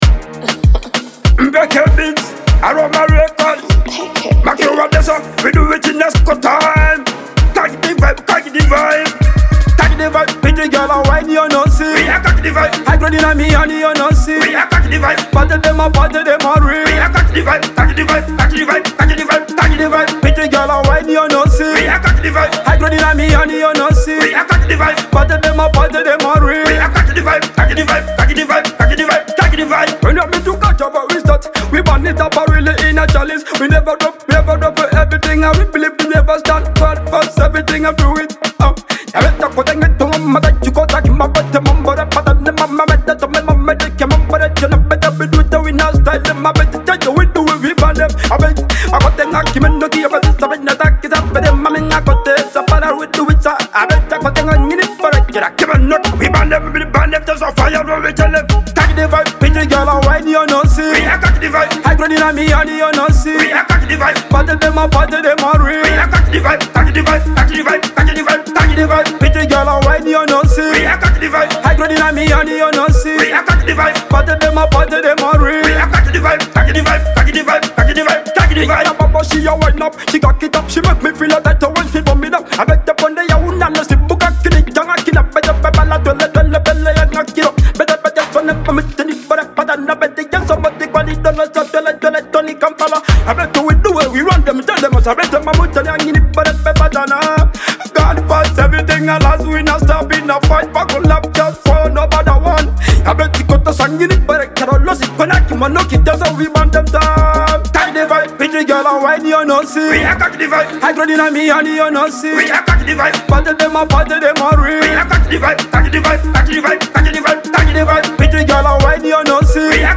a vibrant Ugandan banger
Afro-fusion production